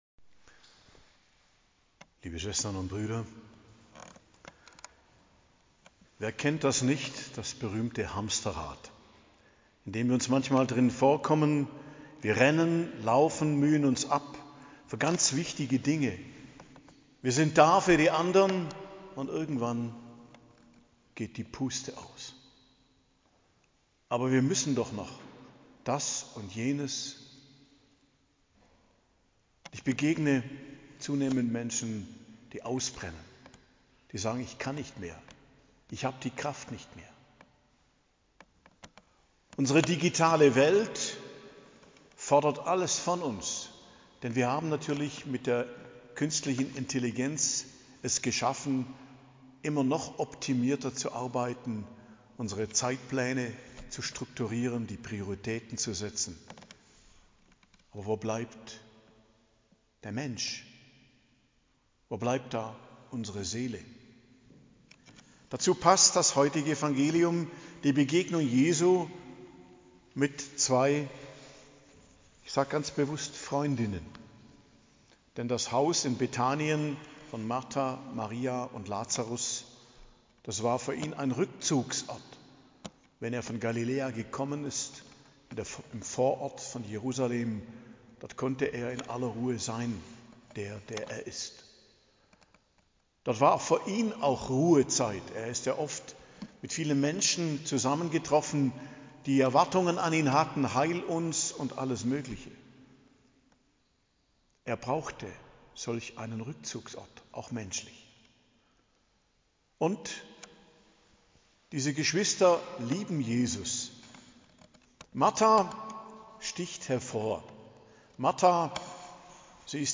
Predigt zum 16. Sonntag i.J., 20.07.2025 ~ Geistliches Zentrum Kloster Heiligkreuztal Podcast